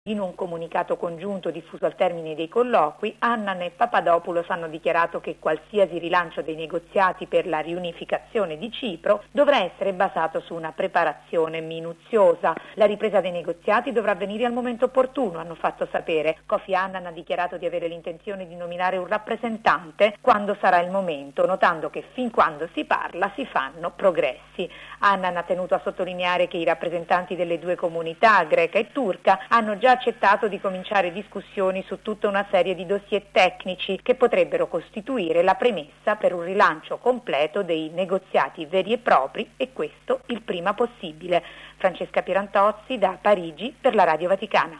In sostanza, bisognerà lavorare ancora molto per la riunificazione delle entità greca e turca dell’isola di Cipro. Dalla capitale francese